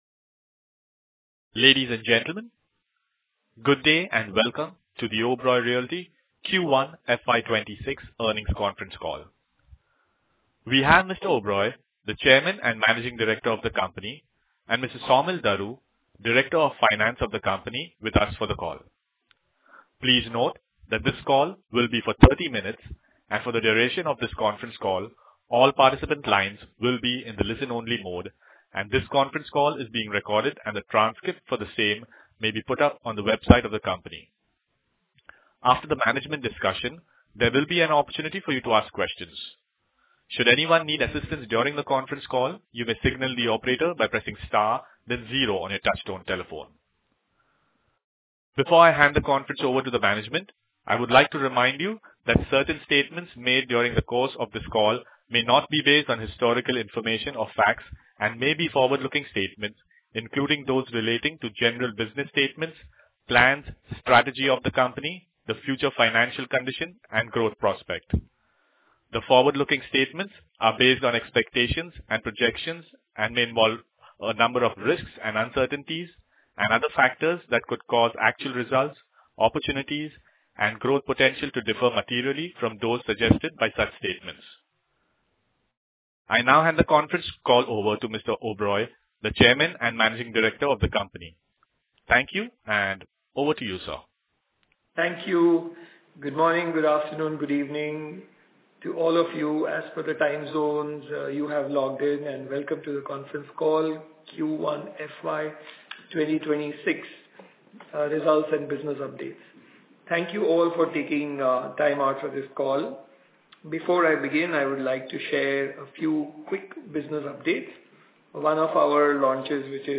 Financial Statements Released for the Q3, FY 2025-2026 Concall Audio Recording Conference Call Transcript Investor Presentation Press Release View all reports